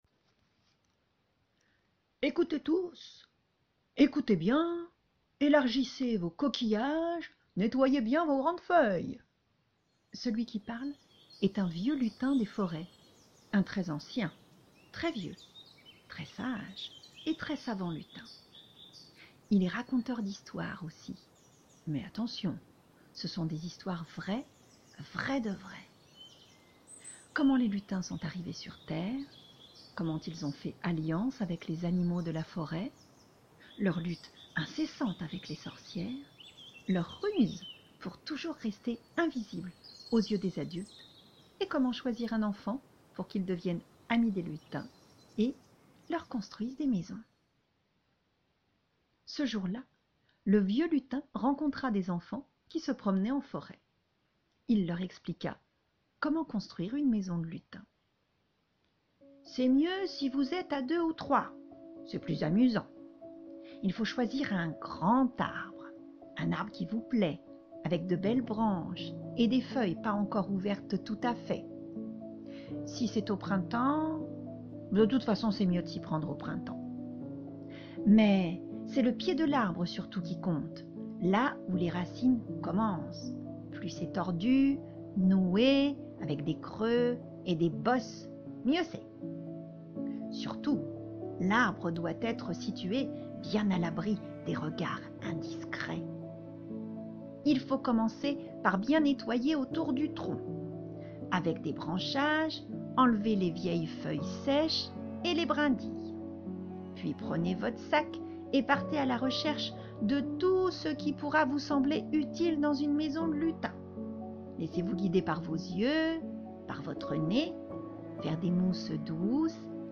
Ecouter le conte